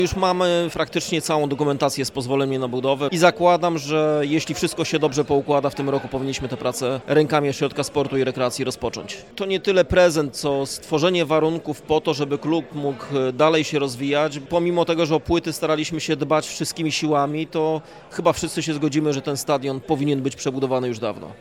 Pierwsze prace już się zakończyły – informuje prezydent miasta, Rafał Zając.